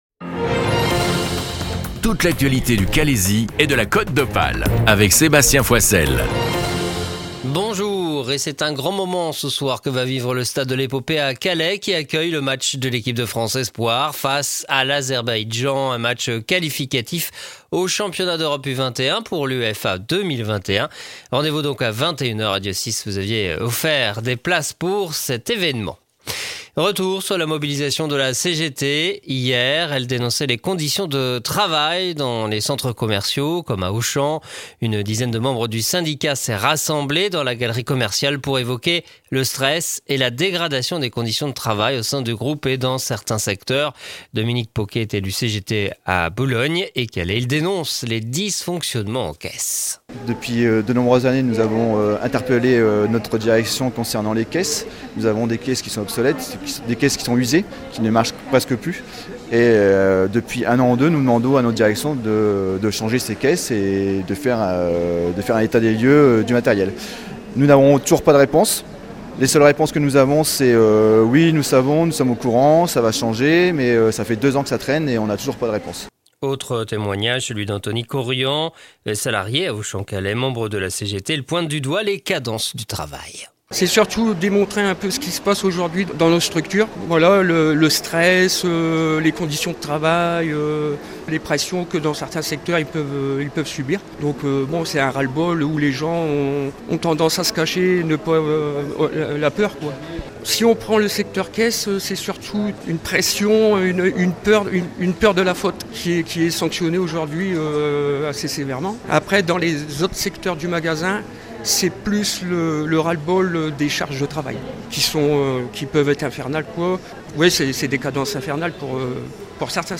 Le journal du jeudi 10 octobre dans le Calaisis